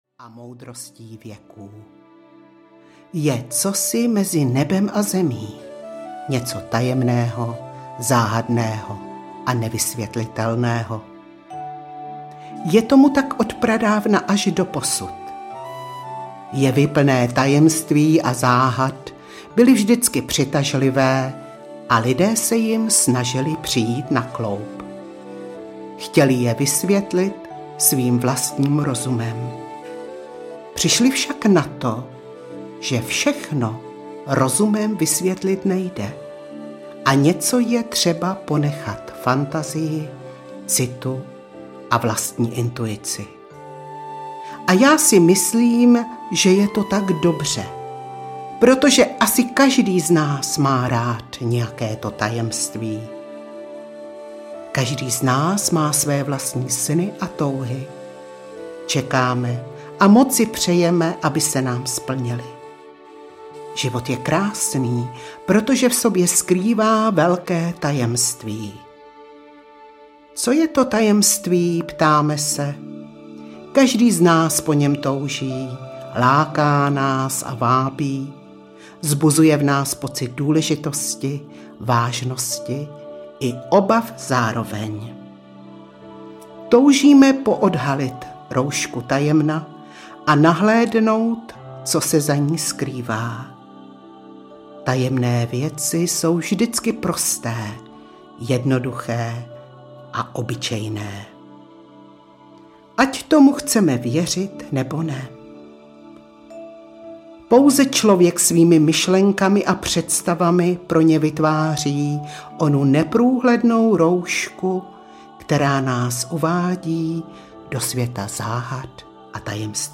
Tajemno kolem nás audiokniha
Ukázka z knihy